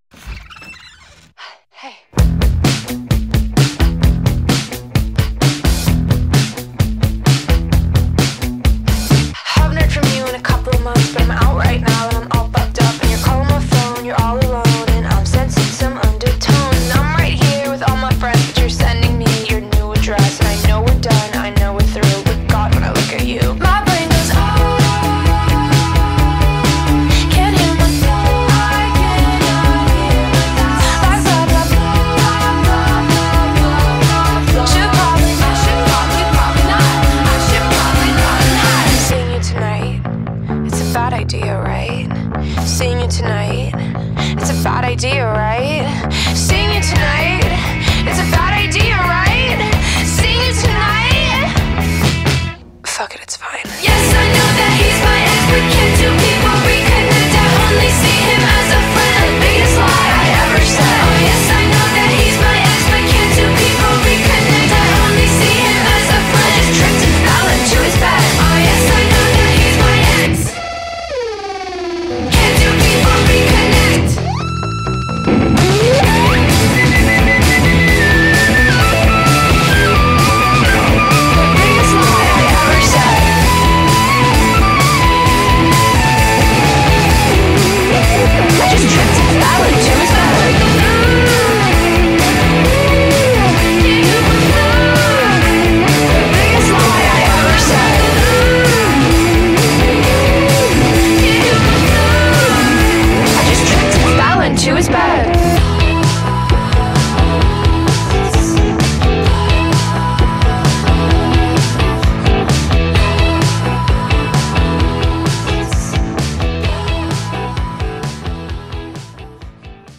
BPM130
Audio QualityPerfect (High Quality)
rock song